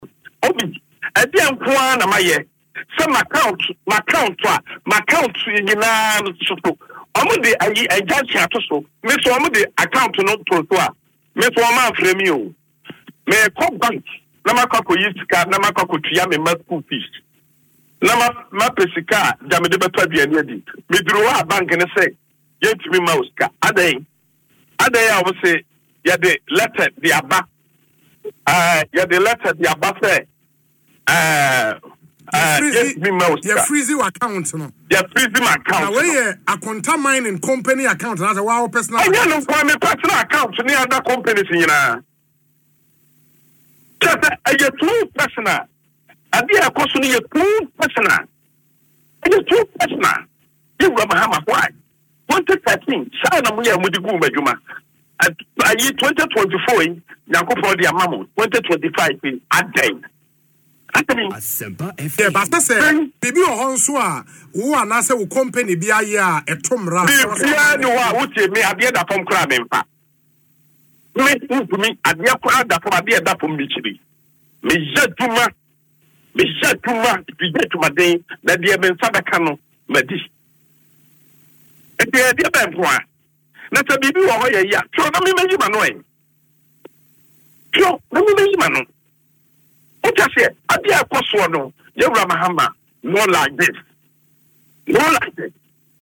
Speaking on Asempa FM’s Ekosii Sen programme, Chairman Wontumi revealed that both his personal and company accounts were frozen without any prior notification.